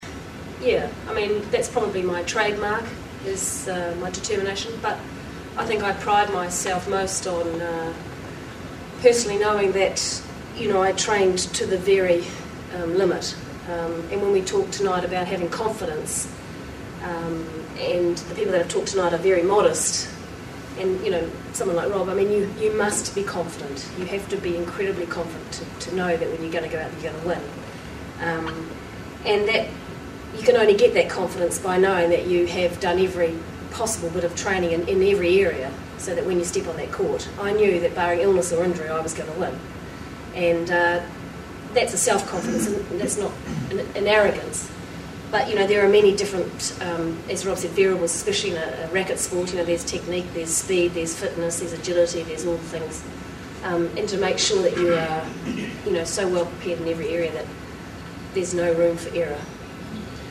These sound tracks were produced from videos made at each of the Peak Performance Seminars.
Video and audio quality is not good for all events due to local venue conditions.